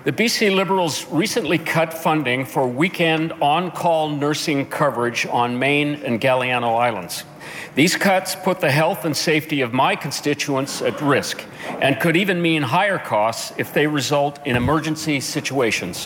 He made his case during Monday’s Question Period at the Legislature